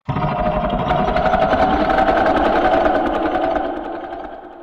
FigureGrowlHigh.mp3